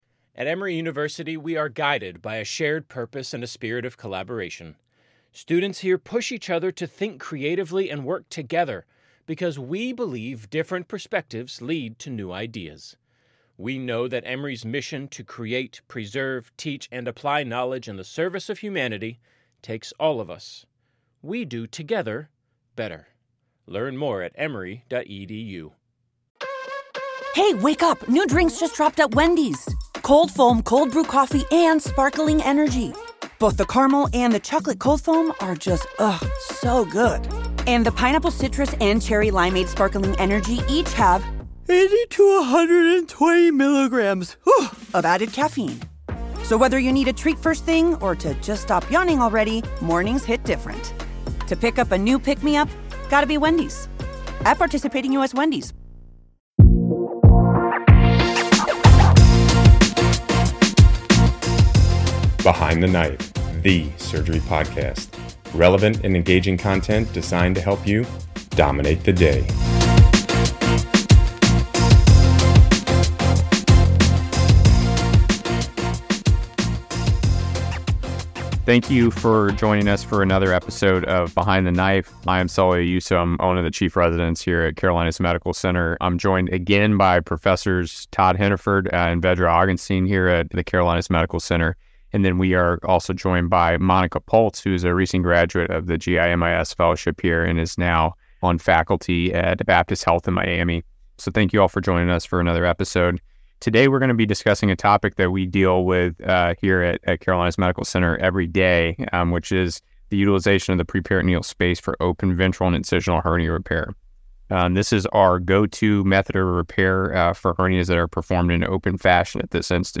Although uncommonly performed, a preperitoneal approach offers several advantages including the ability to achieve large mesh overlap without the need for myofascial release. The team discusses their tips and tricks for utilizing the preperitoneal space in even the most challenging hernia cases.